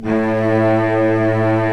Index of /90_sSampleCDs/Roland LCDP13 String Sections/STR_Vcs I/STR_Vcs2 f Slo